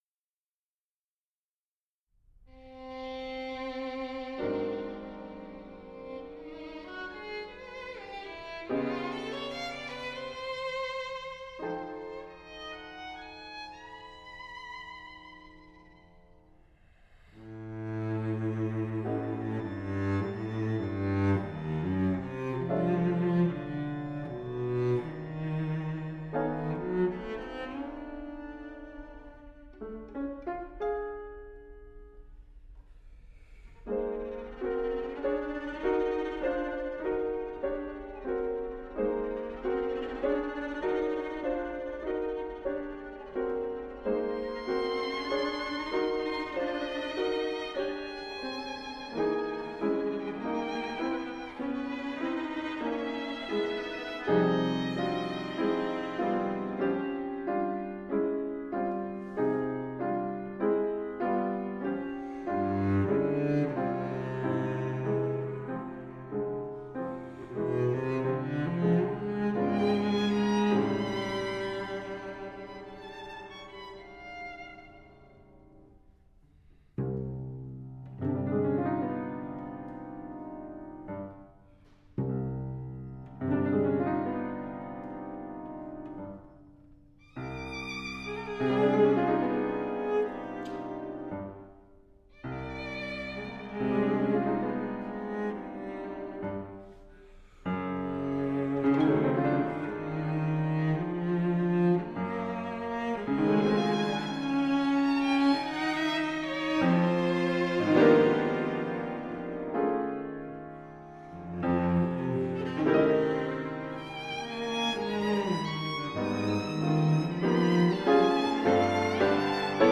for Violin, Cello & Piano
Performed and recorded at the
Plymouth Church of the Pilgrims
Brooklyn Heights, New York
Romanza-for-violin-cello-piano-6-2014.mp3